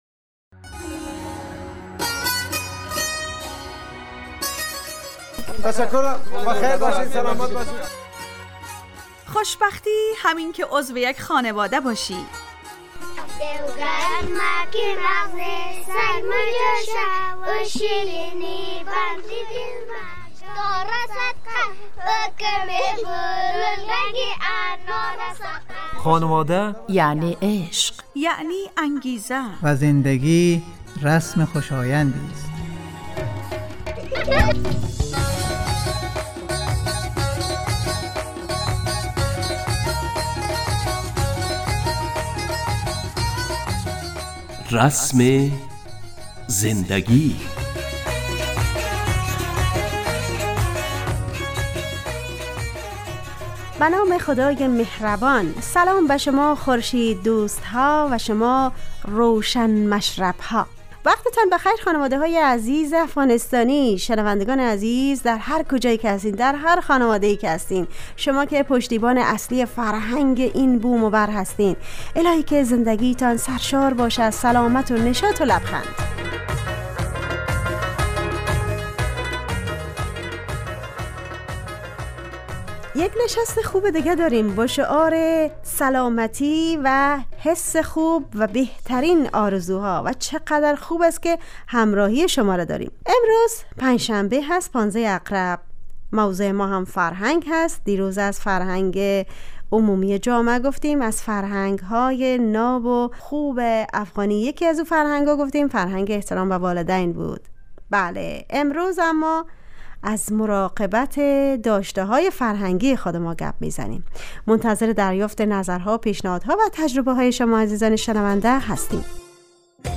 رسم زندگی_ برنامه خانواده رادیو دری